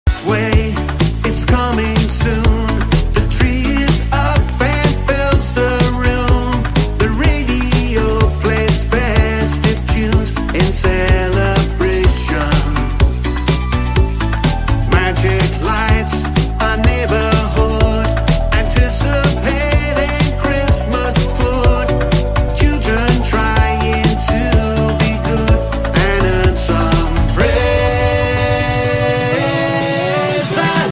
joyful beat